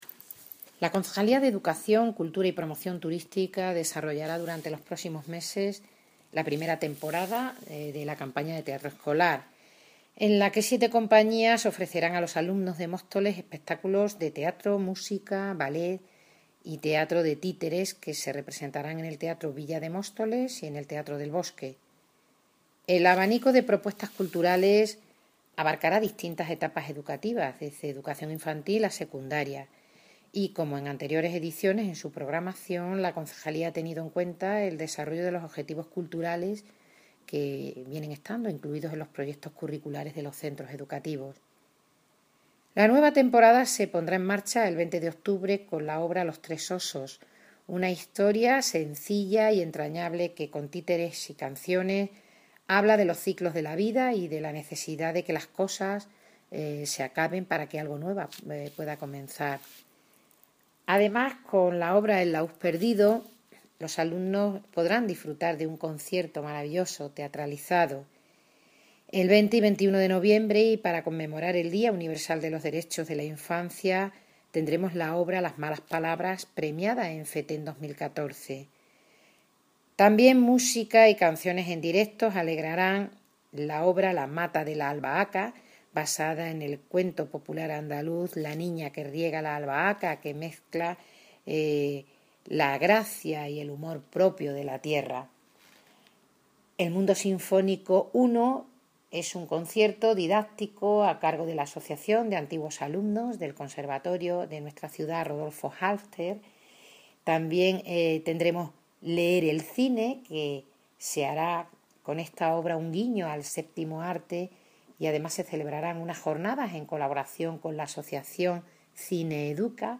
Audio - Mirina Cortés (Concejal de Educación Cultura y Promoción turística) Sobre Campaña Teatro Escolar